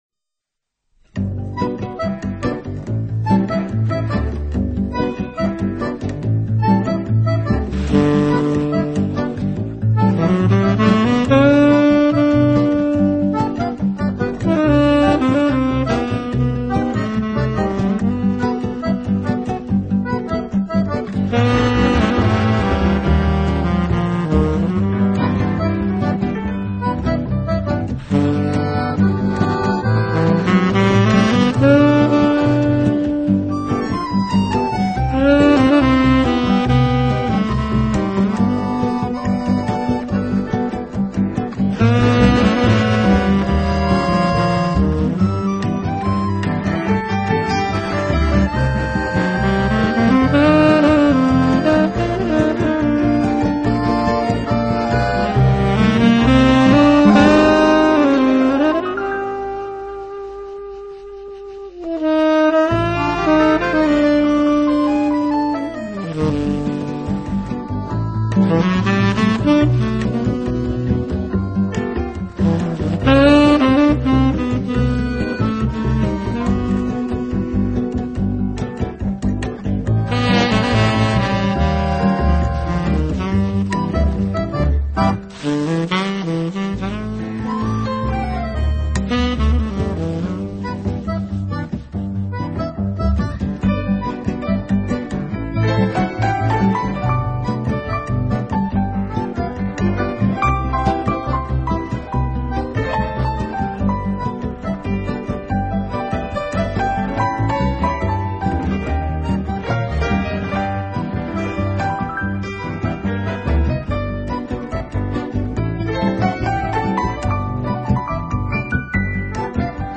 【轻柔爵士】
俐落的吉它，配上超重低音的牛筋、浪漫的手风琴和感性的萨斯风，
这张专辑的乐器定位，空间感等皆属天碟级数，不容错过。
带着怀旧味道的人声，高雅且富含表现力，